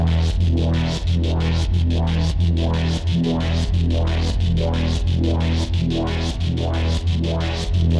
Index of /musicradar/dystopian-drone-samples/Tempo Loops/90bpm
DD_TempoDroneE_90-E.wav